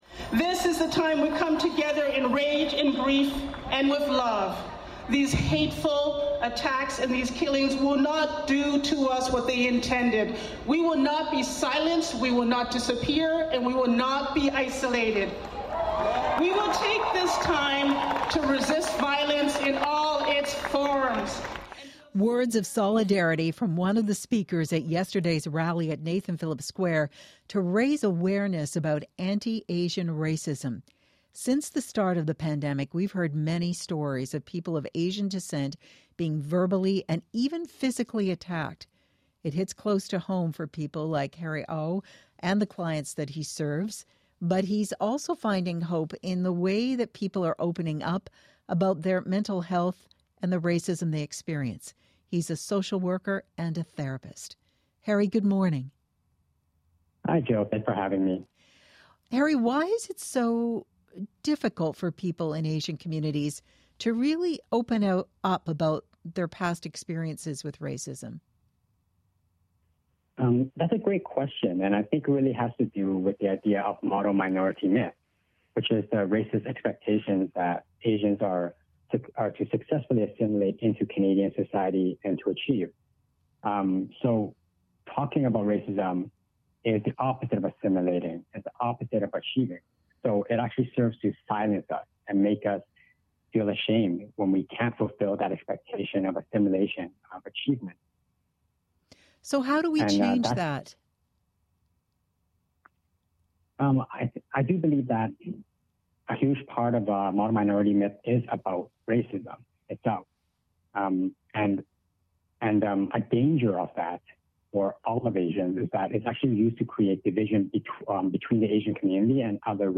Interview with CBC Metro Morning